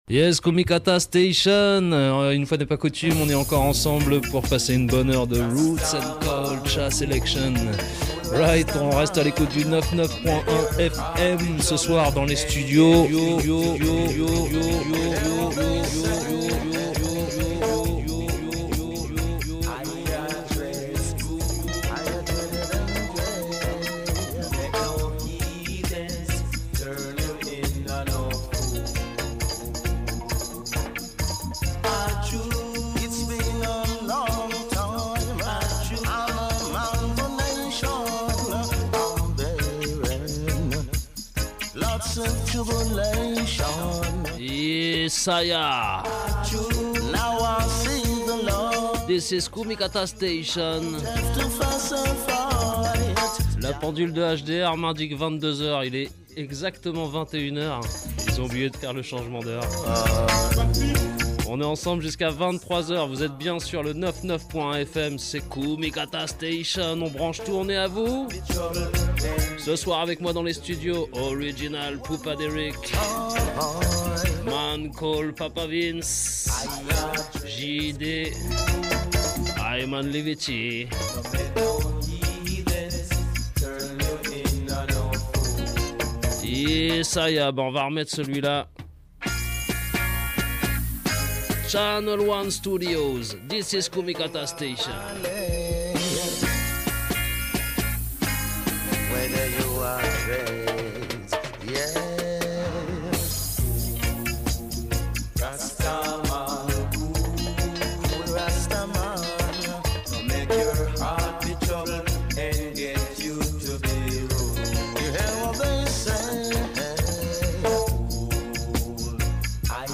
Roots Dub 'n Kultcha !!
Bahhh ...une grosse session roots avec les 3 bros selectas ...